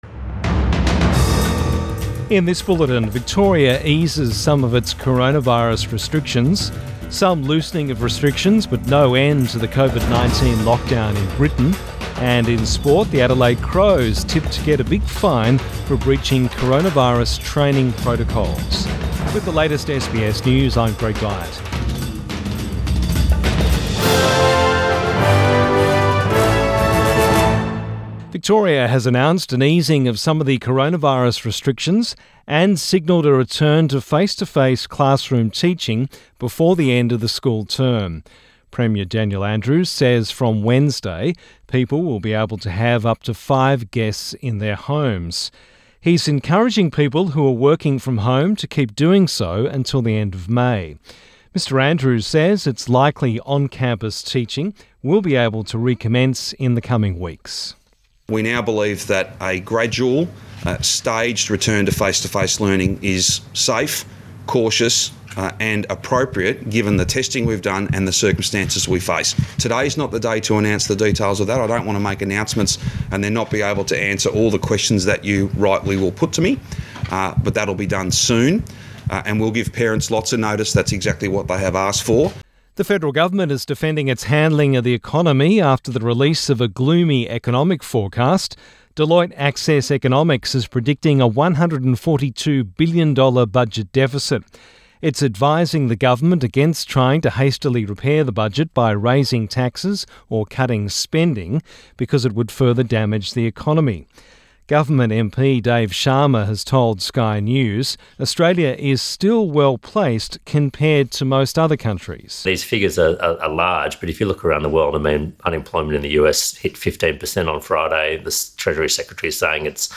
Midday bulletin 11 May 2020